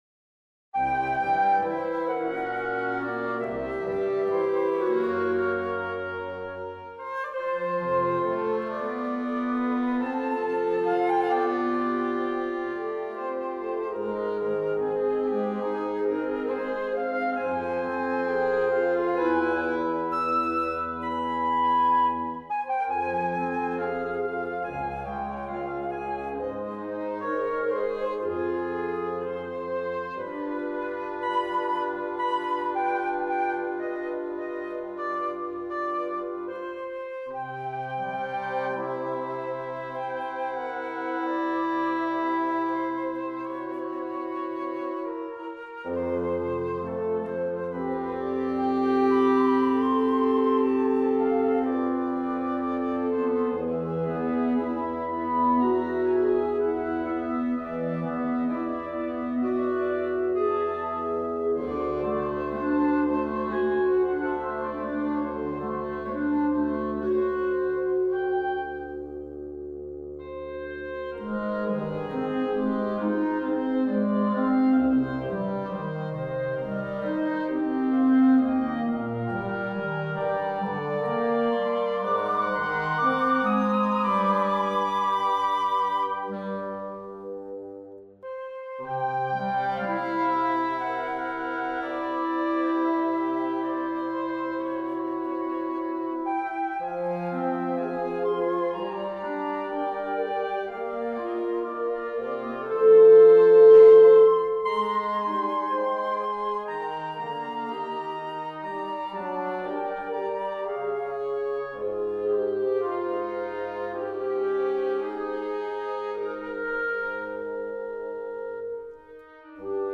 Theme loosely based on hope (Klavier)
Etwas experimentelleres, rhythmisch sehr flexibel und einfach gehalten.
Das ist ein Steinway.